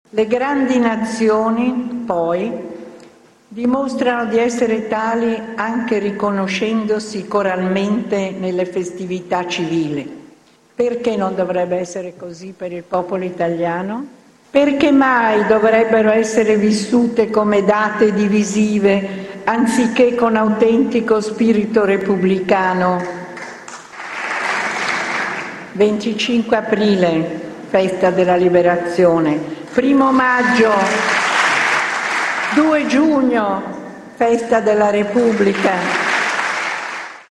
segre-4-senato.mp3